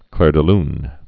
(klâr də ln)